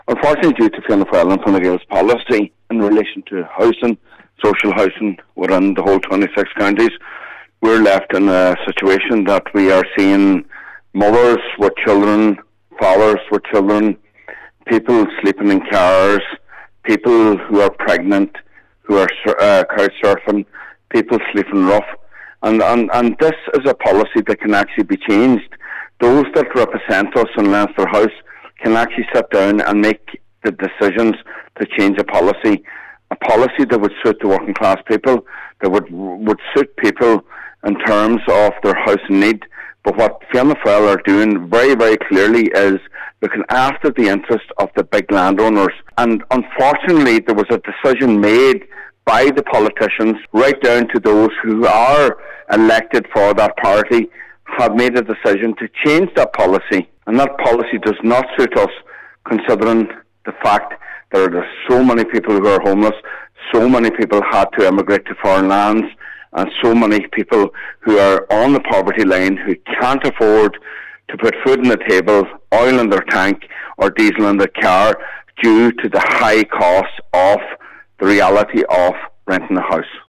Cllr Mac Giolla Easbuig says he is dealing with people who are losing hope and that is unacceptable: